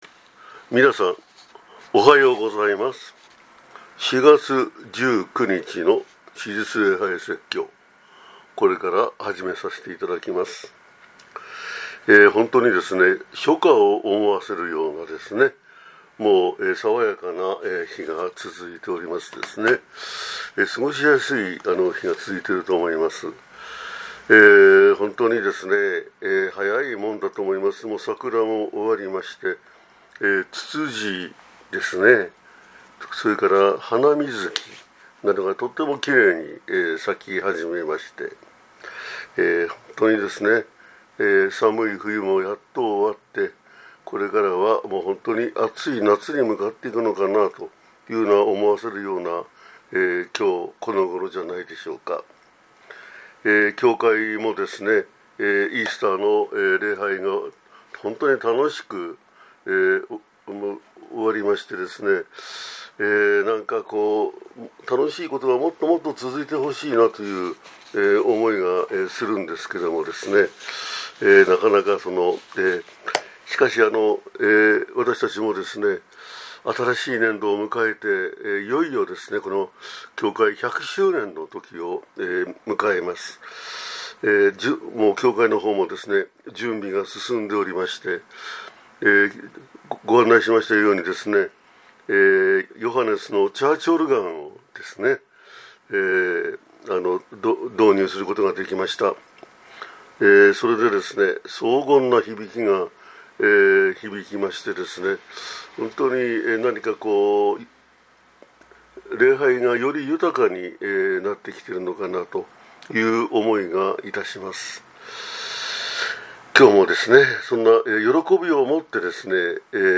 2026年4月19日（復活節第3主日）